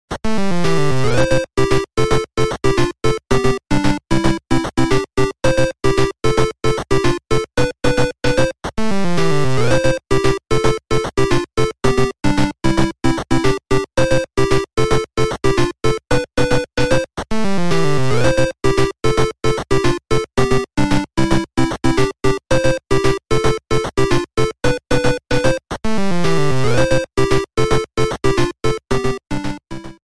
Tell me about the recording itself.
Trimmed to 30 seconds, applied fadeout